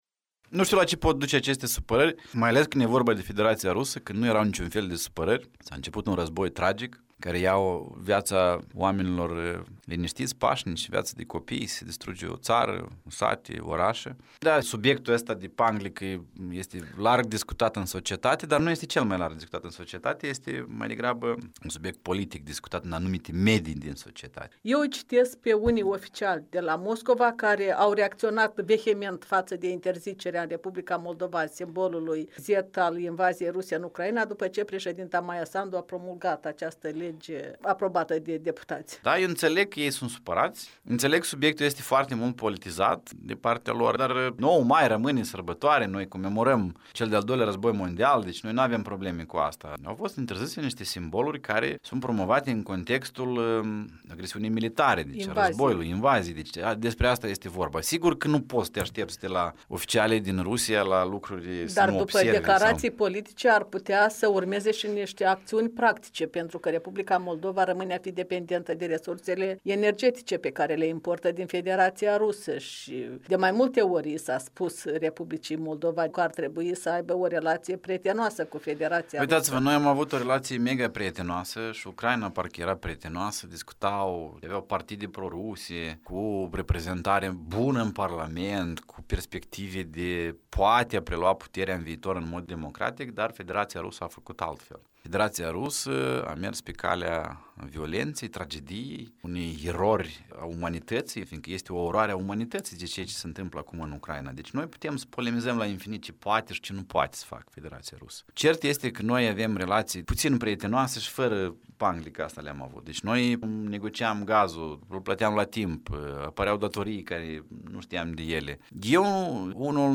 Cum se explică supărările Moscovei pentru interzicerea însemnelor războiului din Ucraina în Moldova? O convorbire cu fostul deputat Vadim Pistrinciuc, directorul executiv al Institutului pentru Iniţiative Strategice.